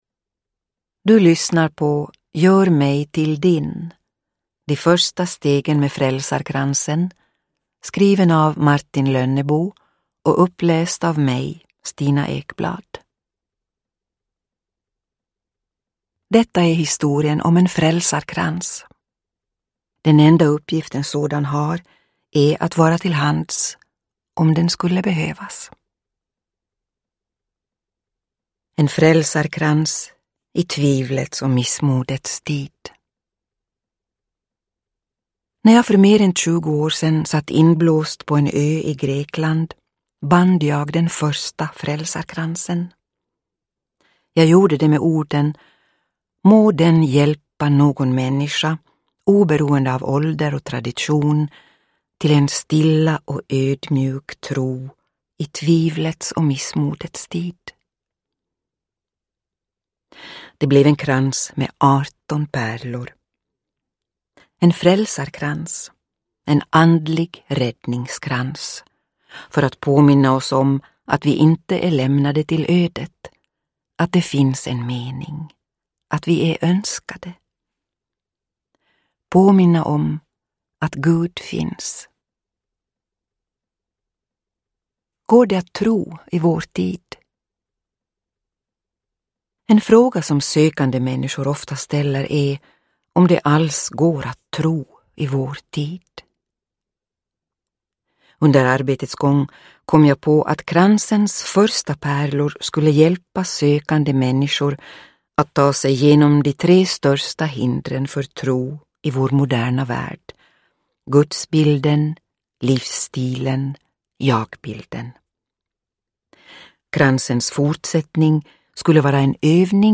Uppläsare: Stina Ekblad
Ljudbok